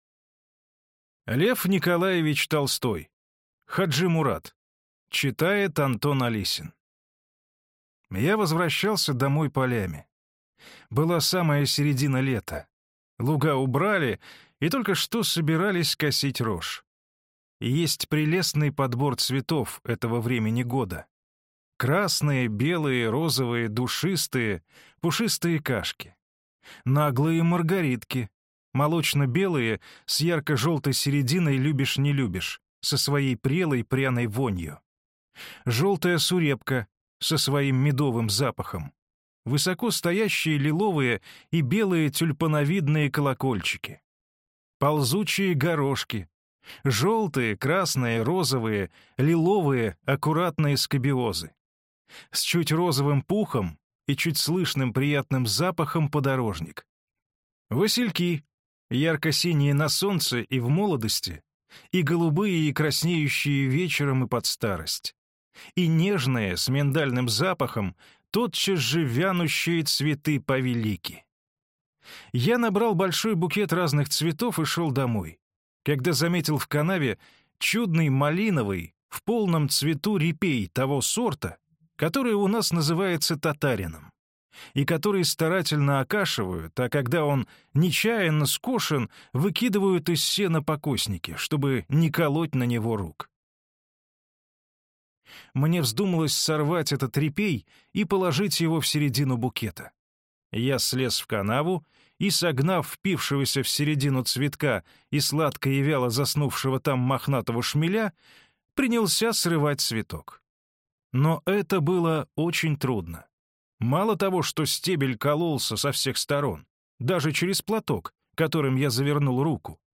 Аудиокнига Хаджи-Мурат | Библиотека аудиокниг